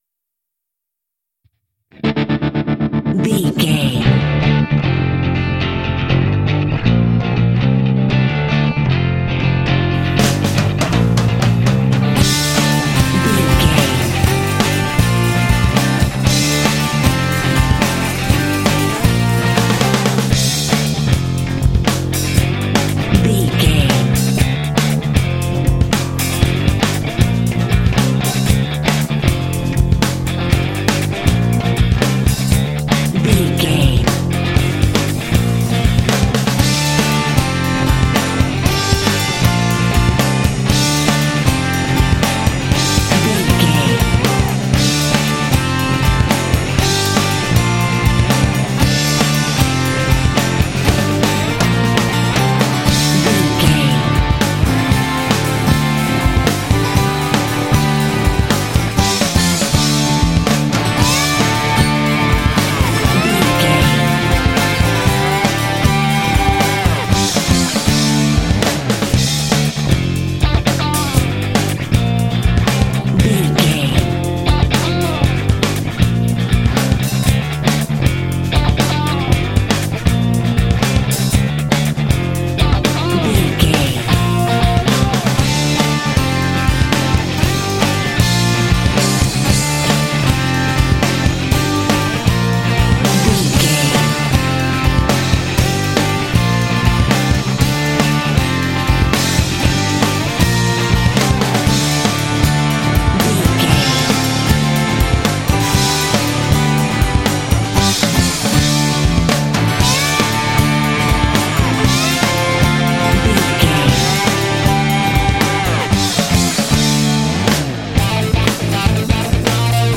Epic / Action
Aeolian/Minor
D
driving
powerful
energetic
heavy
bass guitar
electric guitar
acoustic guitar
drums
heavy metal
alternative rock
classic rock